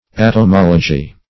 Search Result for " atomology" : The Collaborative International Dictionary of English v.0.48: Atomology \At`om*ol"o*gy\ ([a^]t`[u^]m*[o^]l"[-o]*j[y^]), n. [Atom + -logy.] The doctrine of atoms.